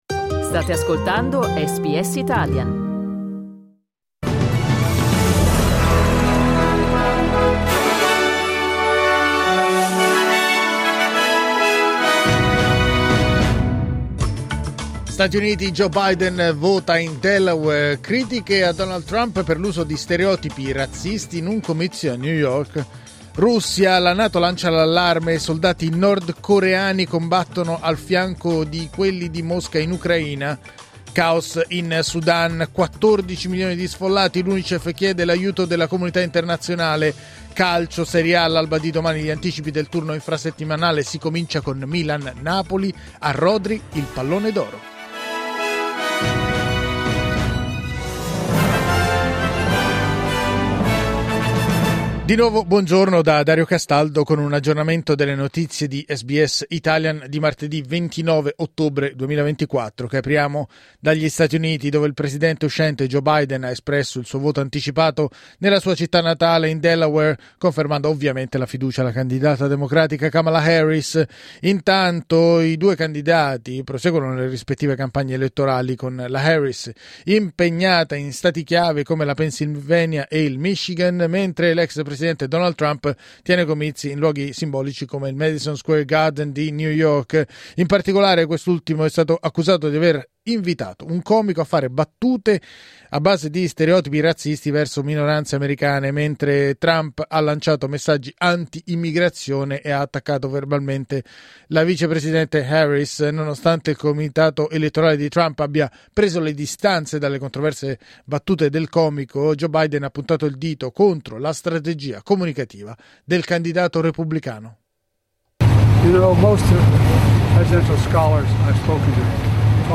News flash martedì 29 ottobre 2024
L’aggiornamento delle notizie di SBS Italian.